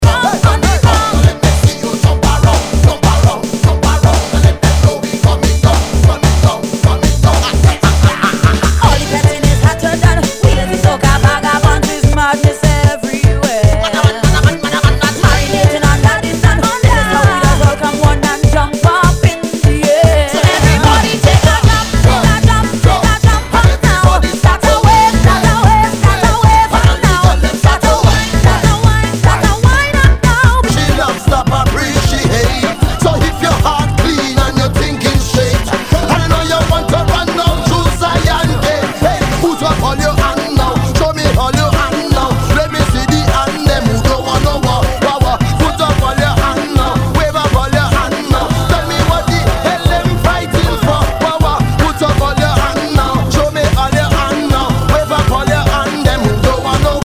HOUSE/TECHNO/ELECTRO
ナイス！ユーロ・ラガ・ハウス！